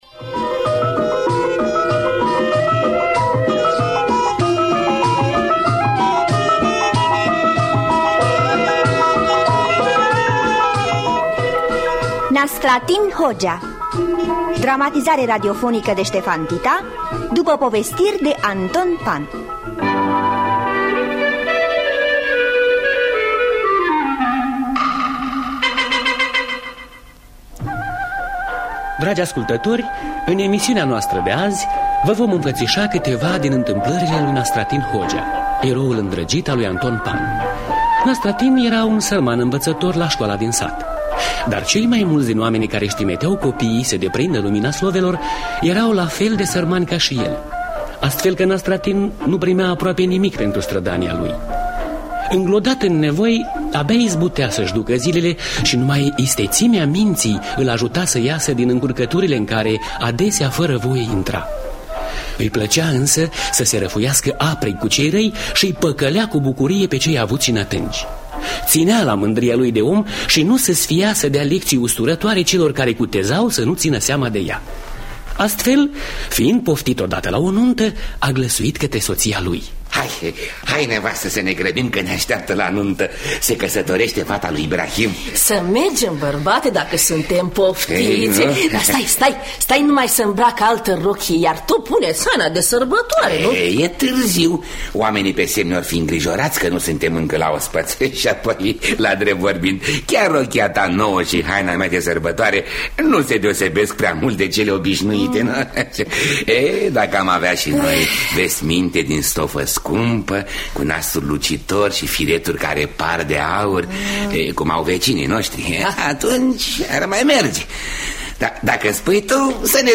Nastratin Hogea de Anton Pann – Teatru Radiofonic Online